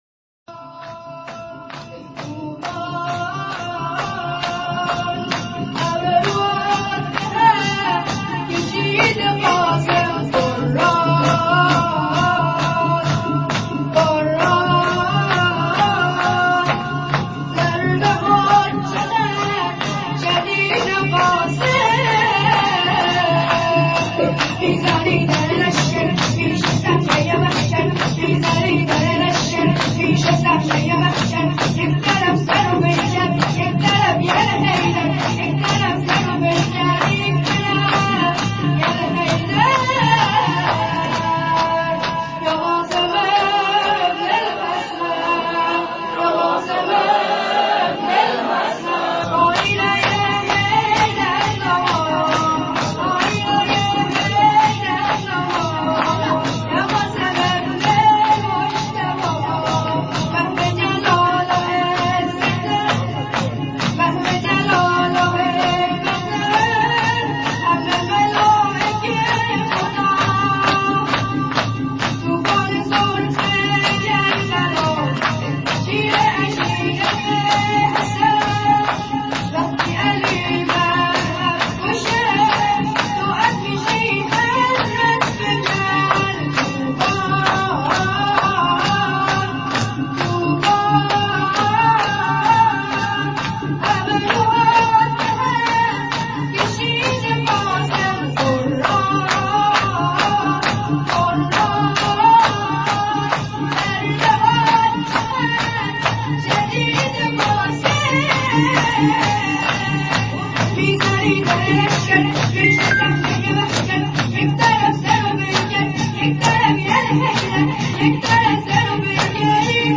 هیئت نوجوانان شهدای گمنام